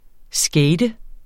Udtale [ ˈsgεjdə ]